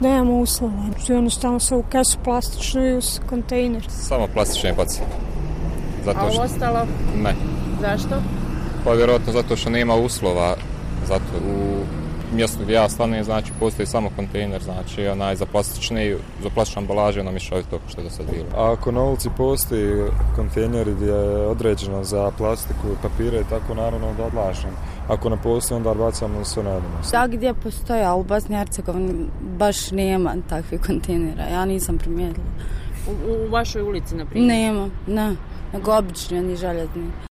To potvrđuju i odgovori anketiranih građana Sarajeva:
Građani Sarajeva